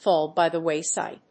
fáll by the wáyside